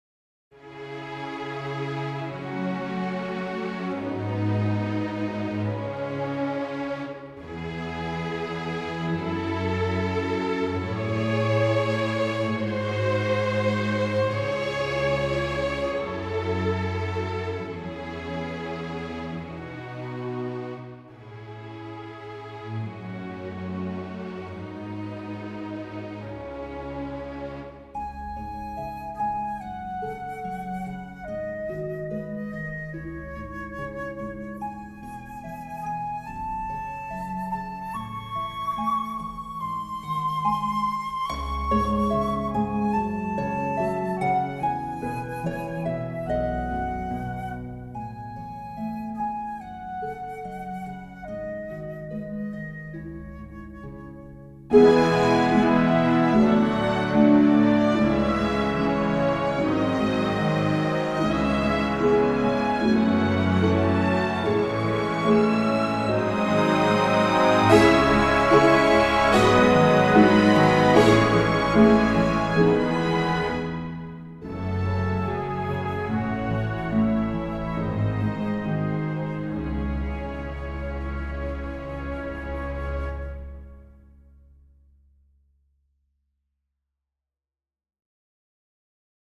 Van de stukken voor orkest is er met behulp van StaffPad een synthetische "weergave" worden gemaakt.
Op.54 No.5 Intermezzo Symfonieorkest augustus 2025 Strijkers, harp, trombones en fluit